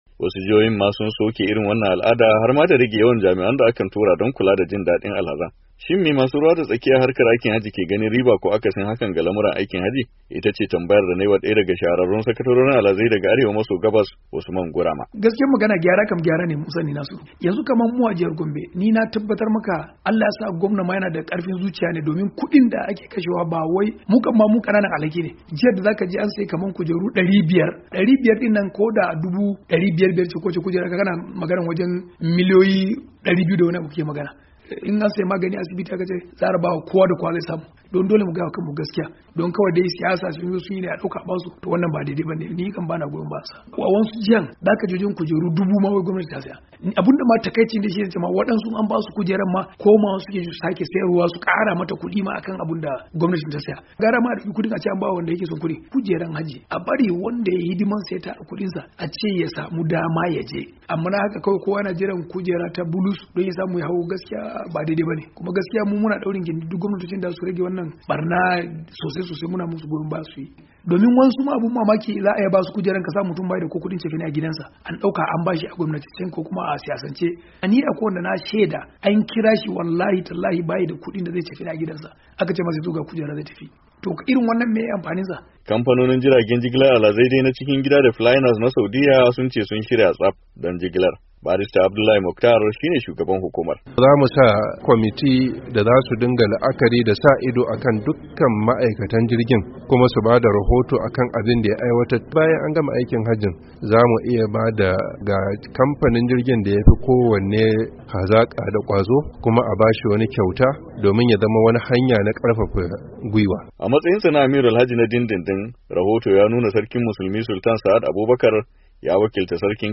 Rahoton aikin hajji-2:06'